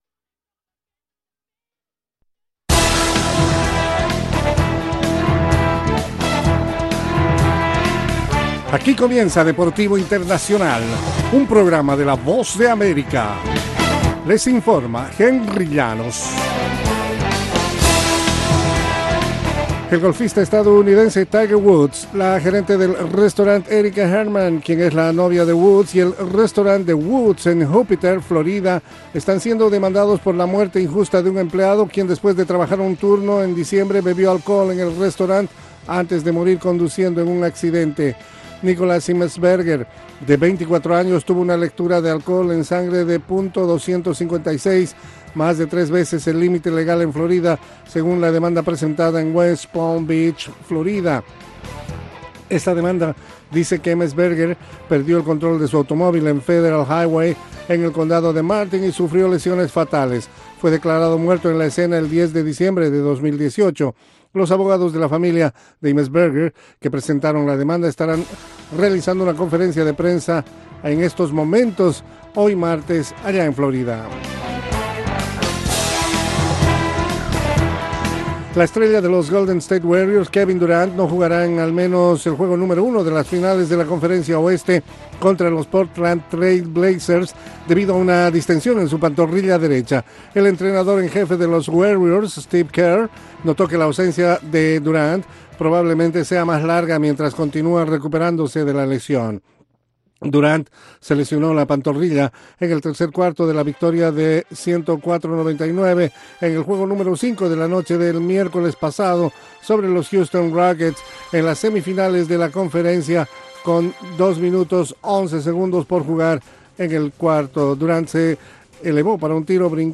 La información deportiva en cinco minutos, desde los estudios de la Voz de América.